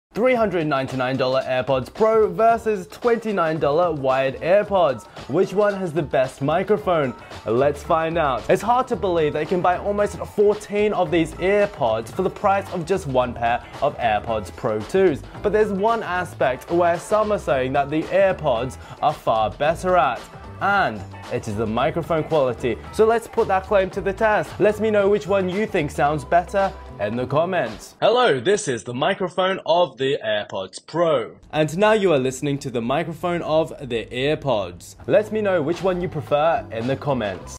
$399 AirPods Pro Vs. $29 EarPods Mic Test